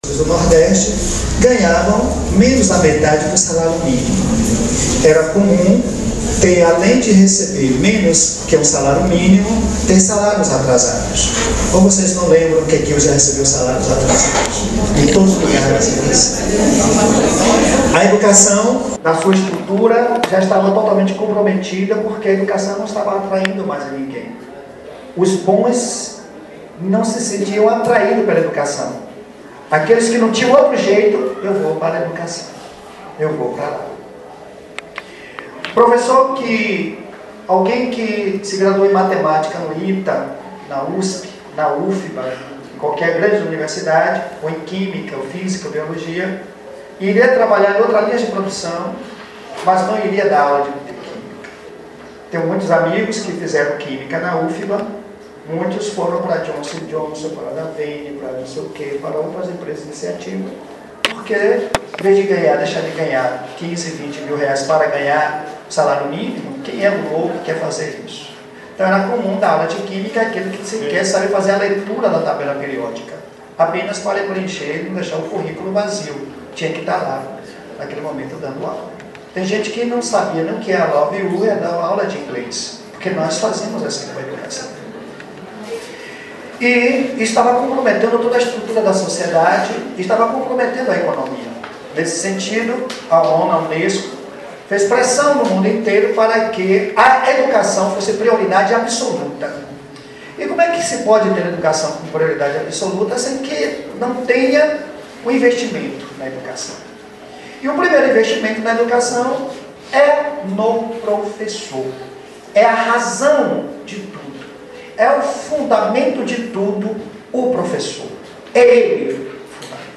Educação e Cultura | Assembleia da APLB traz informação à categoria sobre precatórios